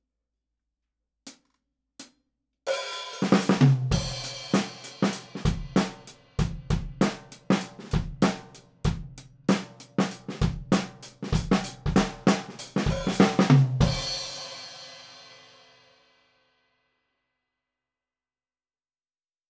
すべて、EQはしていません。
④ドラマー目線
これもバランスよく録れていますね！
ドラム-アンビ-ドラマー目線.wav